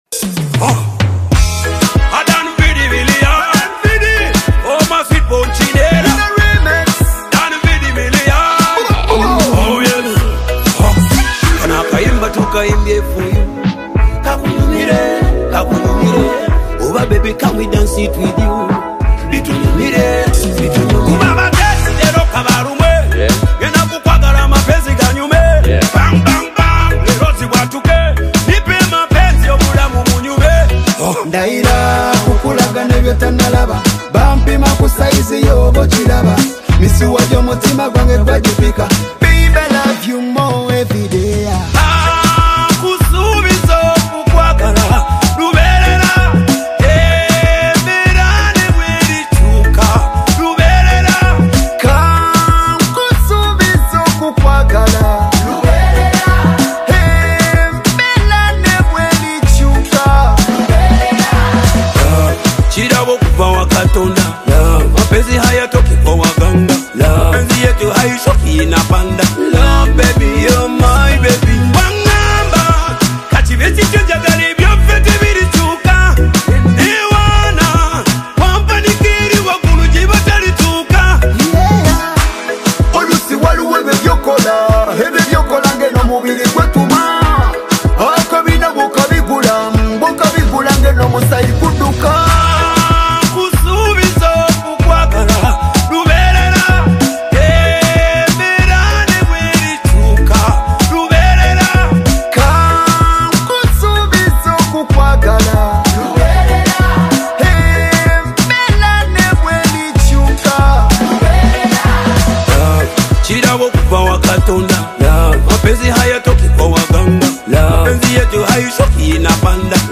Latest Tanzania Afro-Beats Single (2026)
blending catchy melodies with meaningful songwriting.
Genre: Afro-Beats